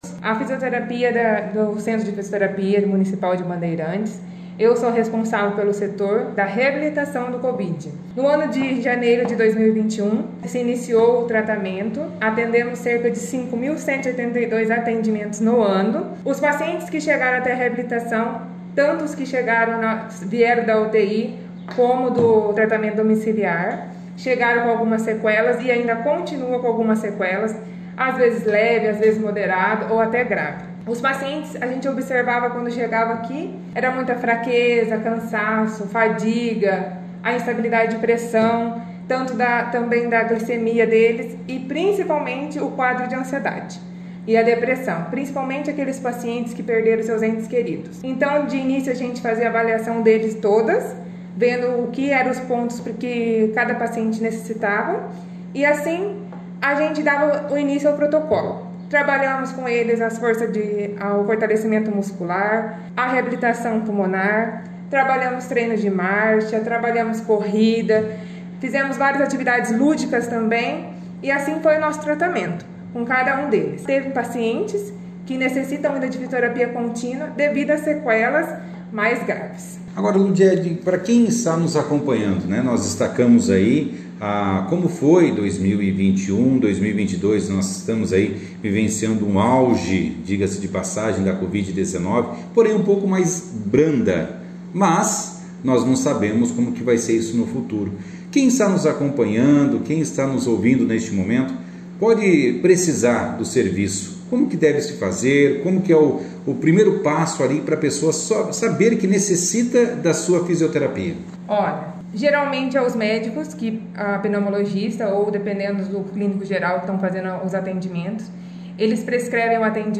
participou da 1ª edição do jornal Operação Cidade